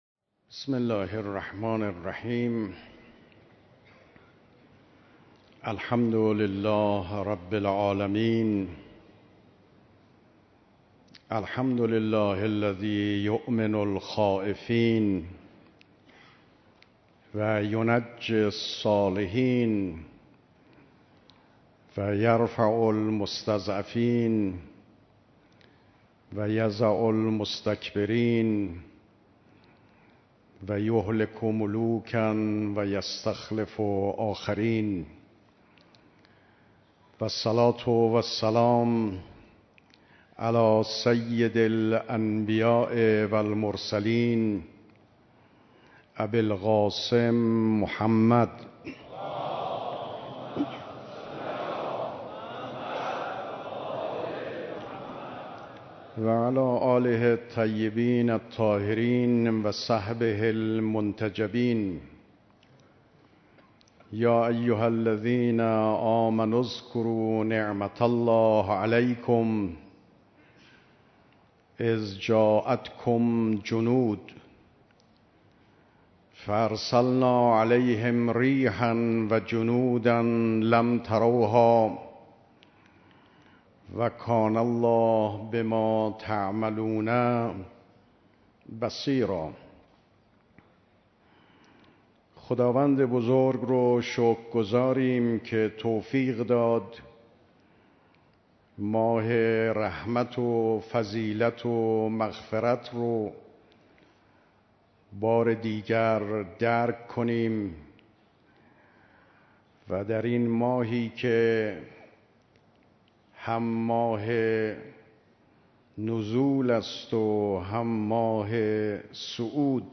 سخنرانی ریاست محترم جمهور جناب آقای روحانی